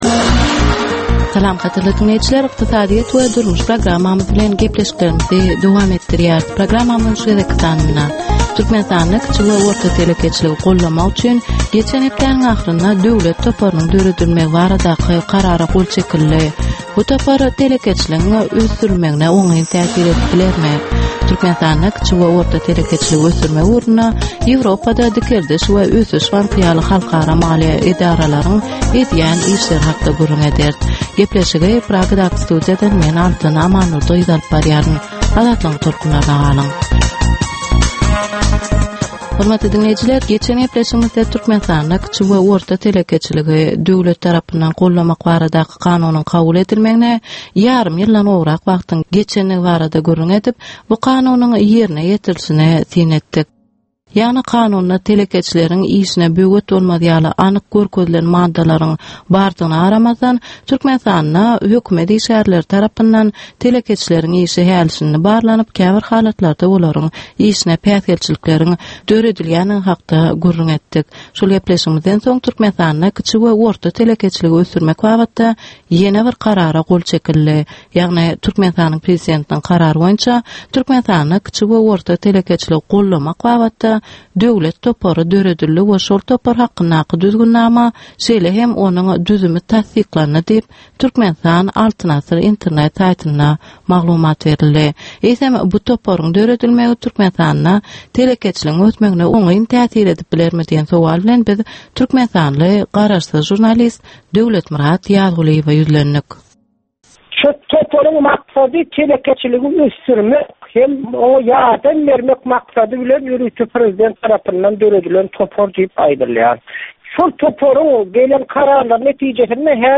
Türkmenistanyň ykdysadyýeti bilen baglanyşykly möhüm meselelere bagyşlanylyp taýýarlanylýan 10 minutlyk ýörite gepleşik. Bu gepleşikde Türkmenistanyň ykdysadyýeti bilen baglanyşykly, şeýle hem daşary ýurtlaryň tejribeleri bilen baglanyşykly derwaýys meseleler boýnça dürli maglumatlar, synlar, adaty diňleýjileriň, synçylaryň we bilermenleriň pikirleri, teklipleri berilýär.